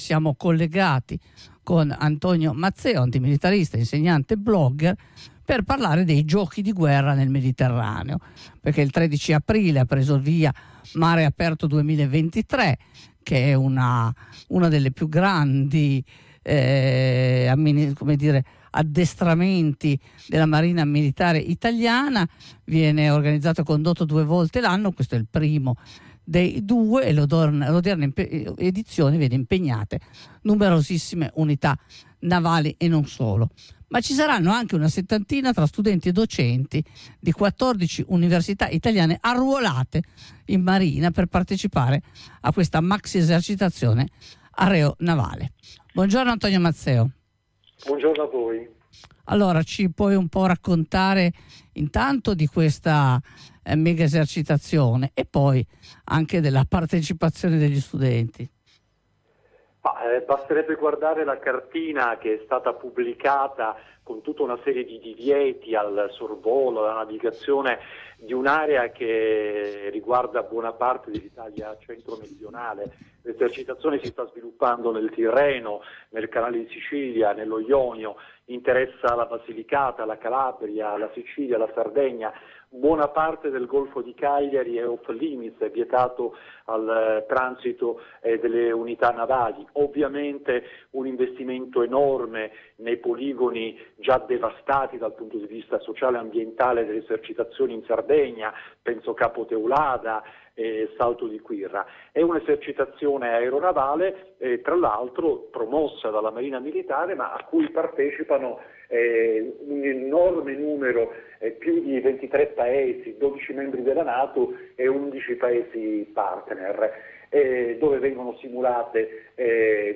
insegnante e blogger Ascolta la diretta: